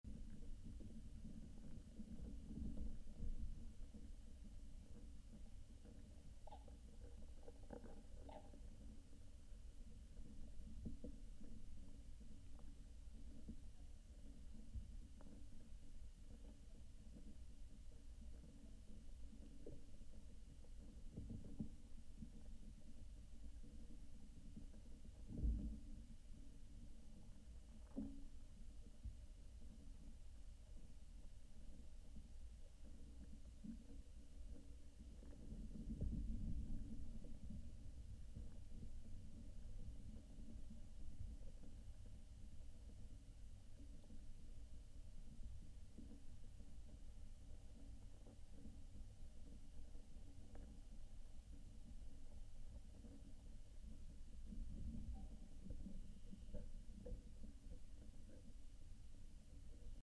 The following audio samples were recorded the day after the soundwalk using the same equipment and locations.
There were also more birds present when I returned alone, picking up the sound of swamp hens and kookaburras through the geofón and hydrophone.
Audio sample from inside the tree on the edge of the lagoon with geofón, Tiger Bay Wetland
Termitesintree_geofon_TigerBay_EDITED.mp3